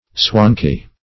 Search Result for " swankie" : The Collaborative International Dictionary of English v.0.48: Swankie \Swank"ie\, Swanky \Swank"y\, n. [Cf. G. schwank flexible, pliant.] An active and clever young fellow.